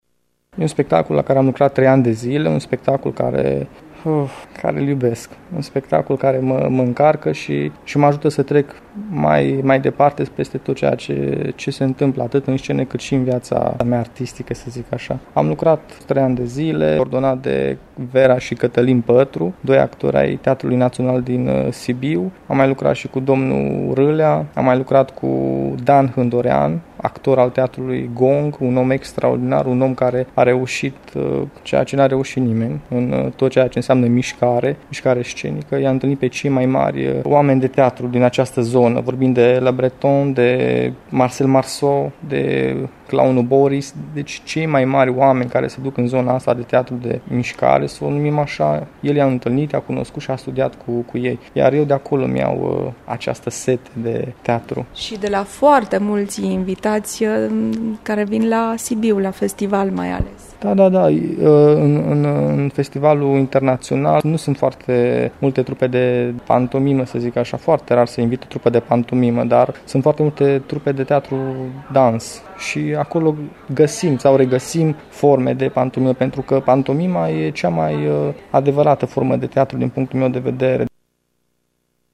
„Pantomima e o artă grea”, îmi spune imediat după reprezentație „de aceea vorbesc așa de greu acum.”